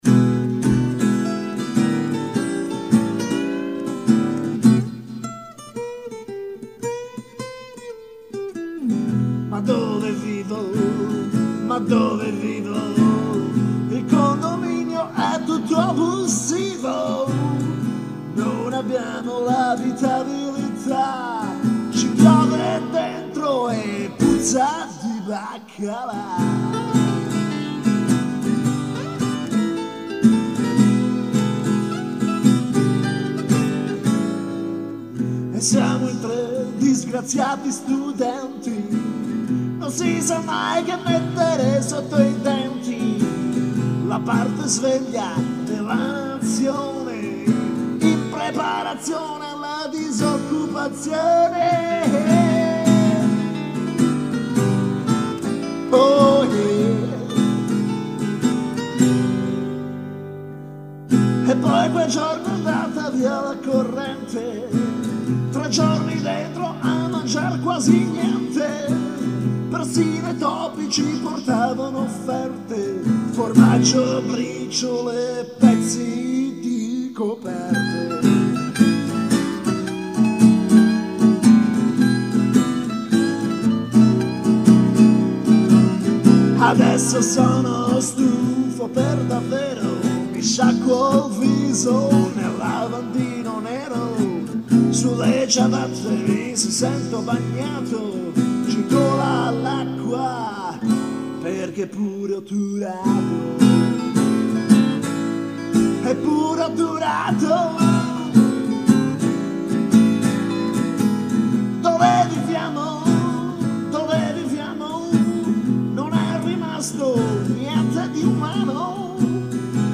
ovviamente non poteva che venire un blues...